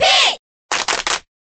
Category:Crowd cheers (SSBB) You cannot overwrite this file.
Pit_Cheer_Korean_SSBB.ogg.mp3